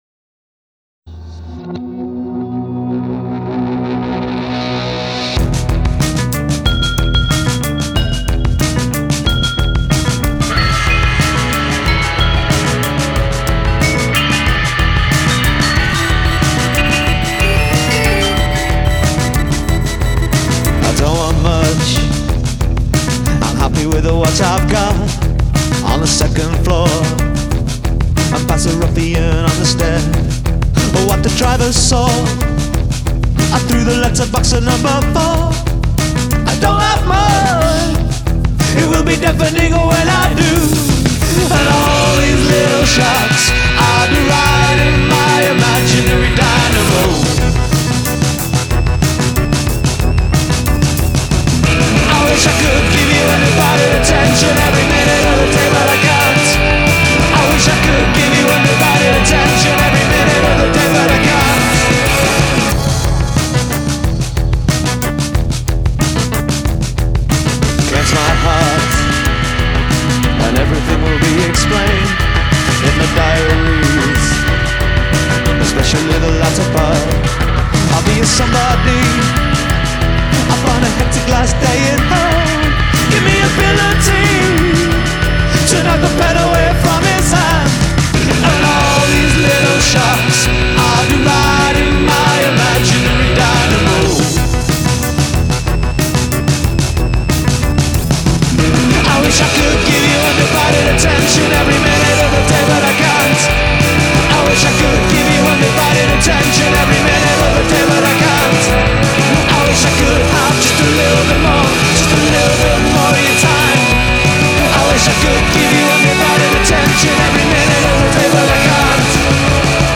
indie band
is certainly noisy new wave